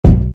nt kick 11.wav